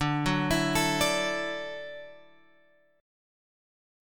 Dadd9 chord {10 9 7 9 7 x} chord